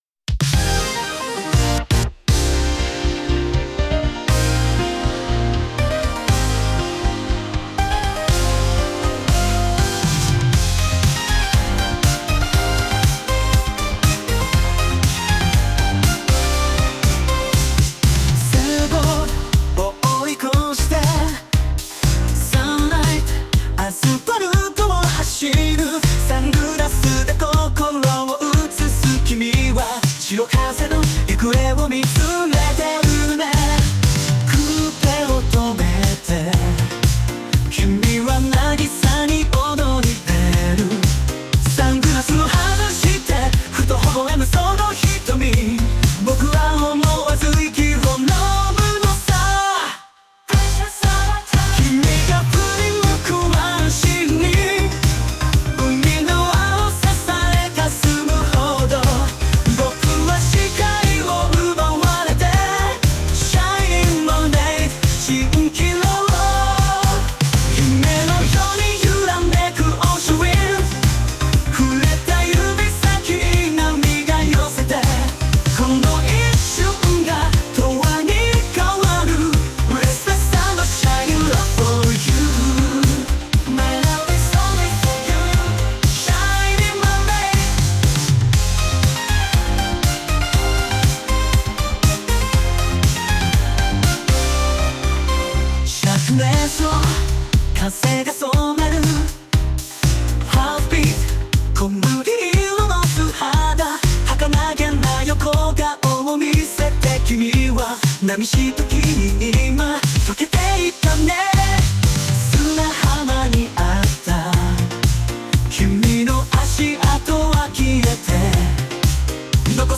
AI生成 懐メロ音楽集